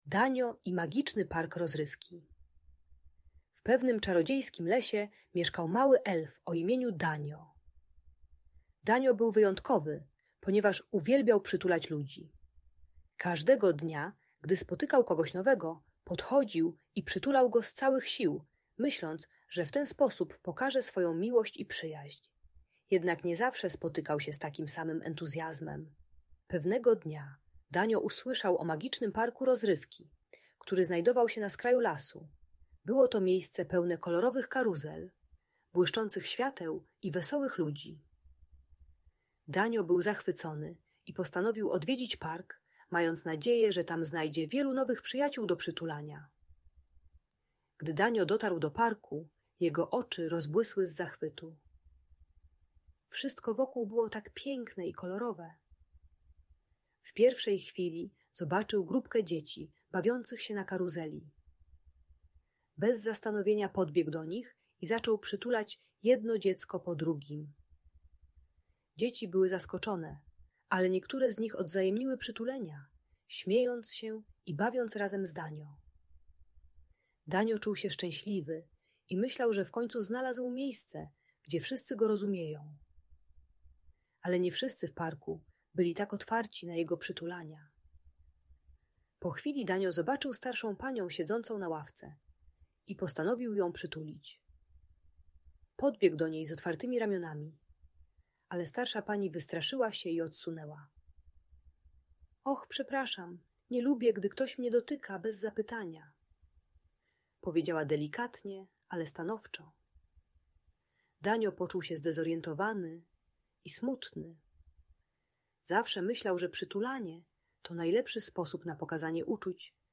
Danio i Magiczny Park Rozrywki - Audiobajka